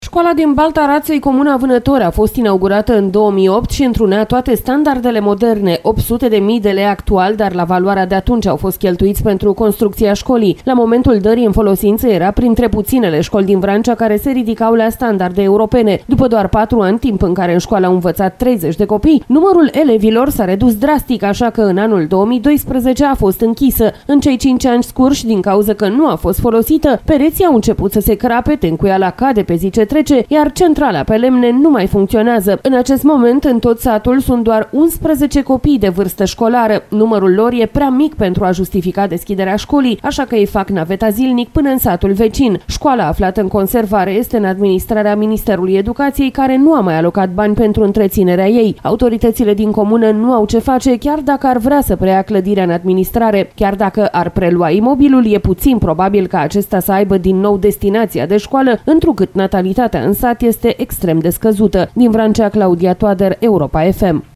Se întâmplă într-un sat din Vrancea, unde noua școală, printre cele mai moderne la vremea inaugurării, nu a funcționat decît 4 ani, transmite corespondentul Europa FM